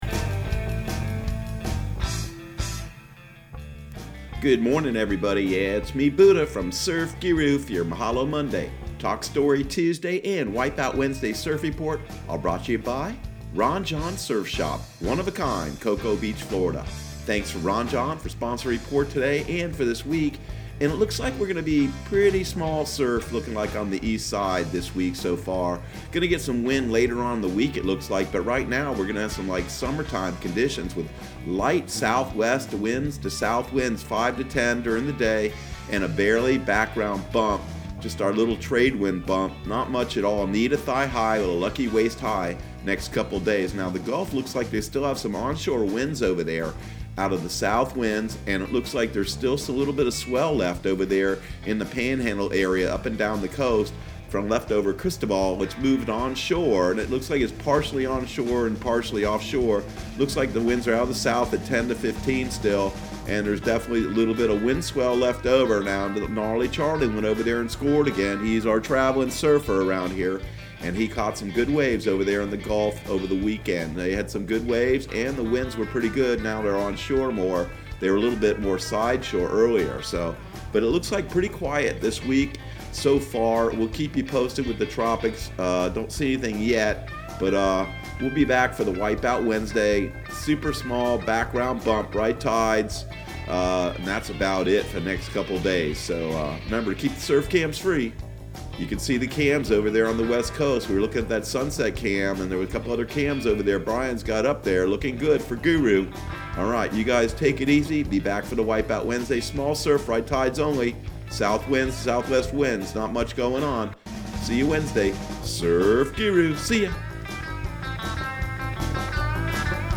Surf Guru Surf Report and Forecast 06/08/2020 Audio surf report and surf forecast on June 08 for Central Florida and the Southeast.